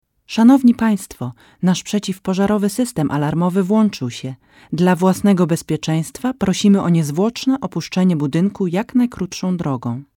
Sprecherin polnisch.
Kein Dialekt
Sprechprobe: Werbung (Muttersprache):
polish female voice over talent